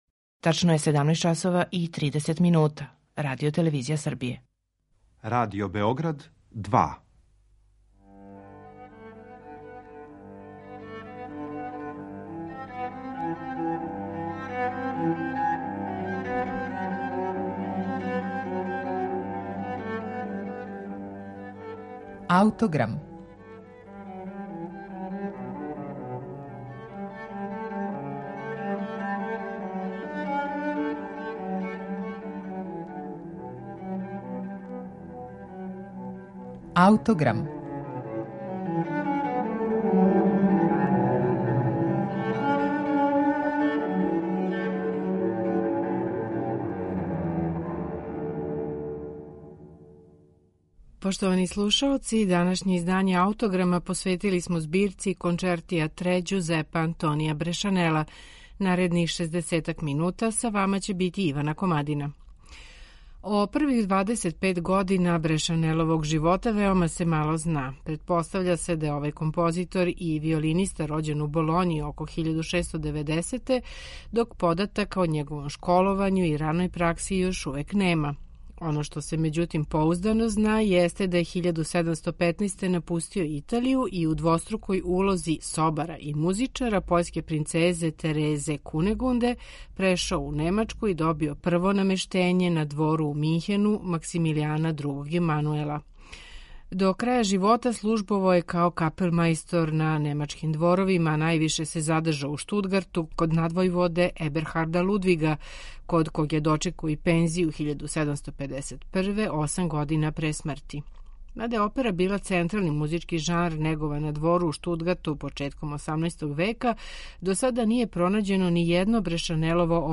По модерним схватањима, ове концерте бисмо пре дефинисали као трио сонате за две виолине и бас, међутим по одредницама Јохана Матесона, Брешанелова дела имају све разлоге да буду названа концертима.
Основна одлика ових концерата јесте тесна испреплетаност деоница две виолне, којом аутор остварује задивљујућ тонски колорит. Шест од дванаест концерата Брешанелове збирке Concerti à 3 слушаћете у интерпретацији ансамбла Der Musikalische Garten , који свира на оригиналним инструментима 18. века.